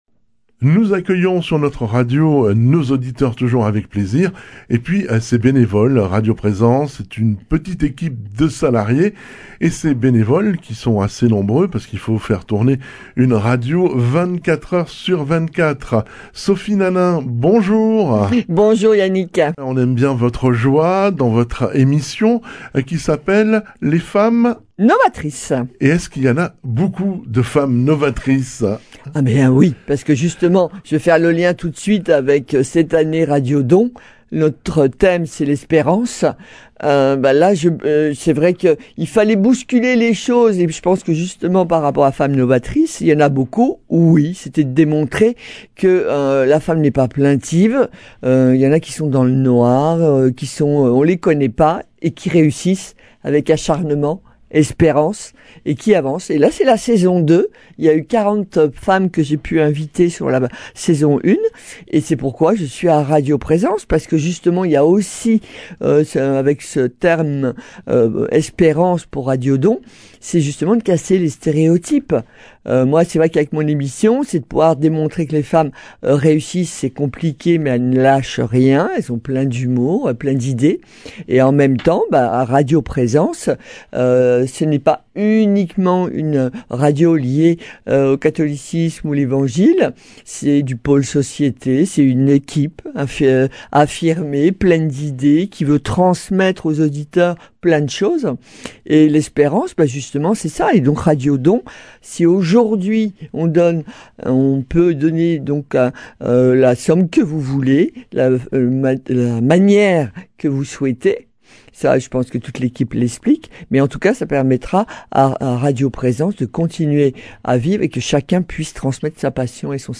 Des voix féminines à l’antenne pleine d’espérance .